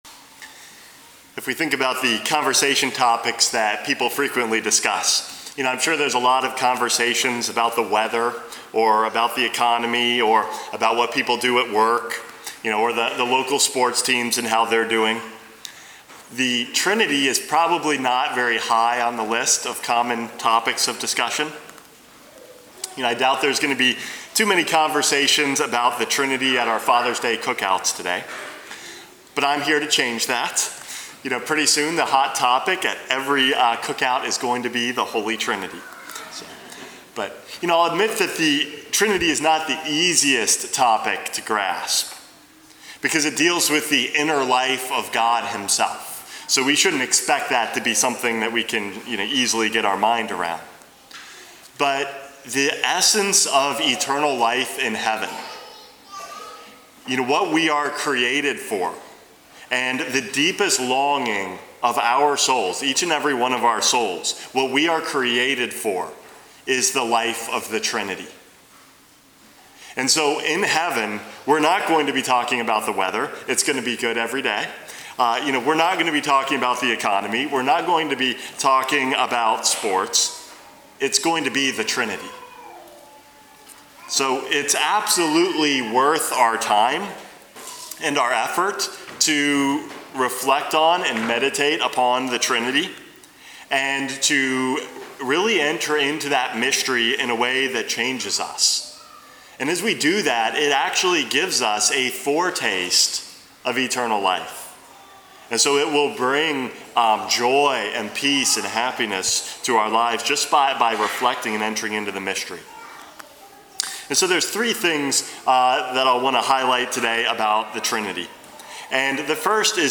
Homily #454 - Life in the Trinity